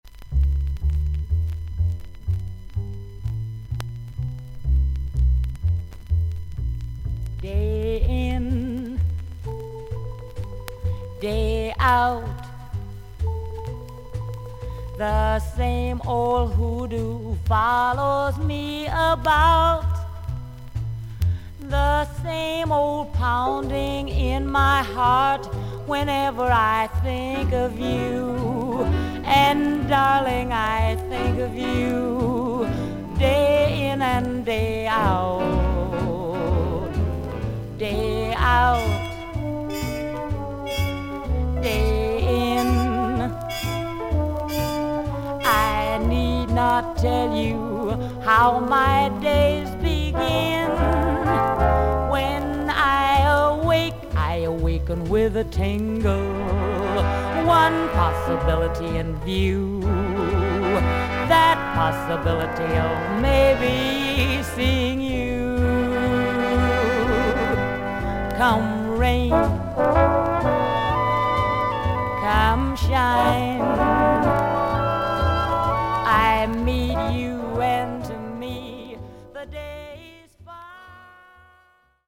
アメリカ盤 / 12インチ LP レコード / モノラル盤
少々軽いパチノイズの箇所あり。少々サーフィス・ノイズあり。クリアな音です。
女性ジャズ・シンガー。
低音ぎみのハスキー・ヴォイスが魅力的。